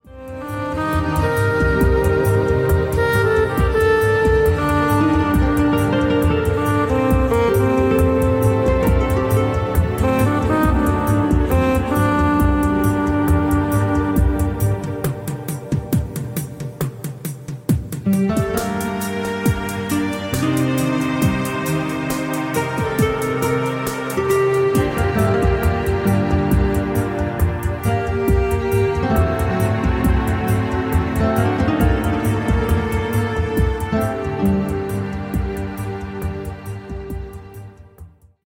CHILLOUT LOUNGE MUSIC